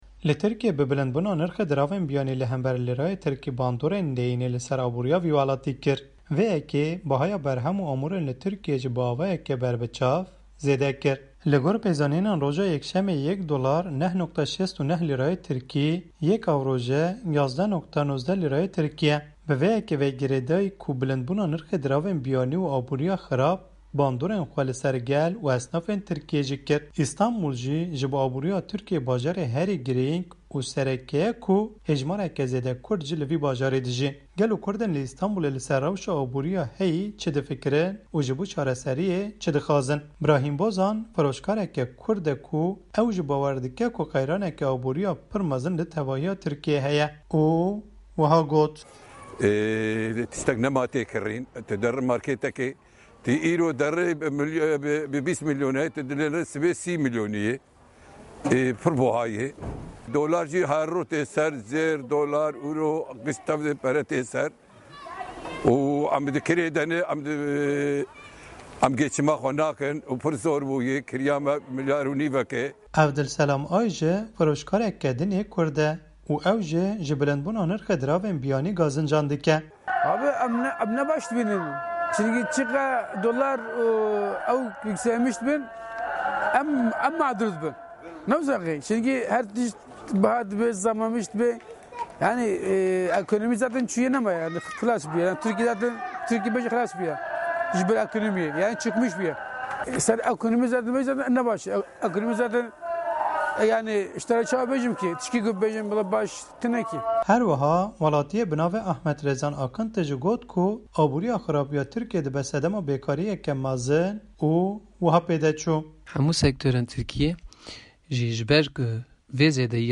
Rewşa Aborî li Tirkiyê, Bazarekî bajarê Stenbul